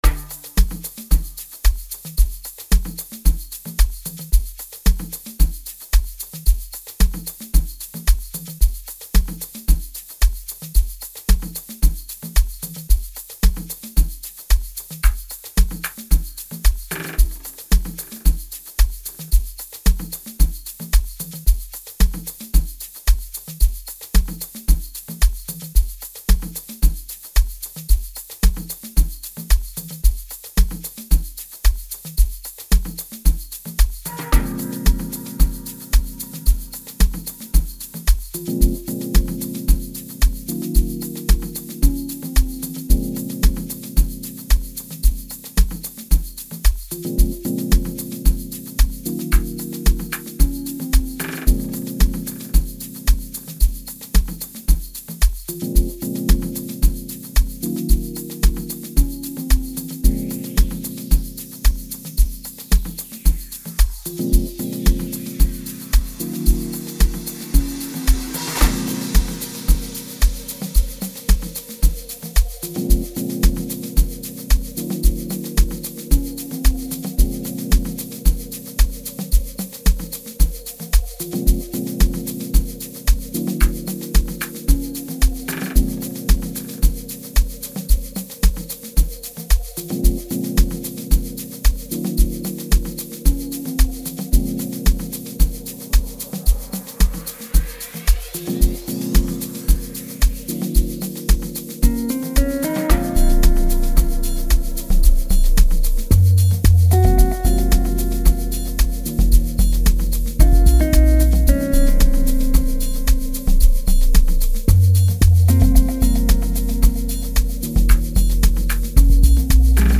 Amapiano Songs